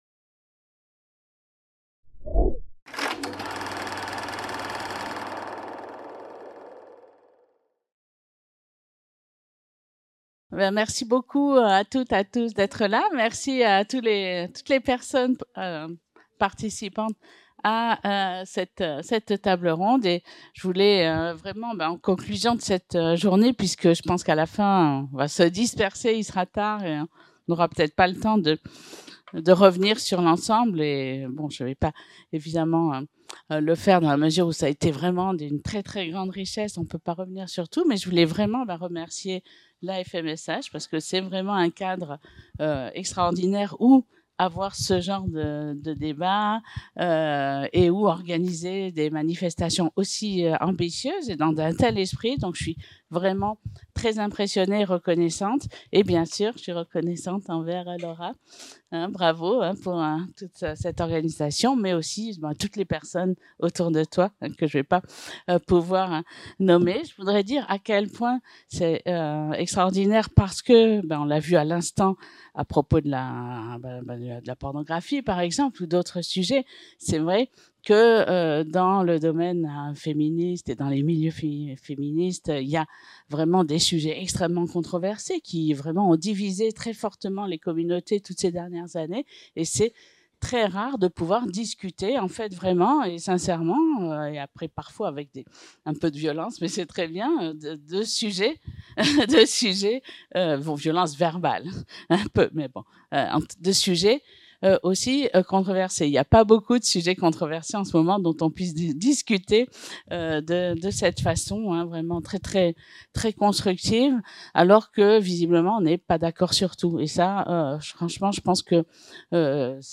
Cinquième session de la journée Sexe et démocratie. De l'enjeu du consentement, qui s'est tenue le 30 mai 2024 dans le Hall de la FMSH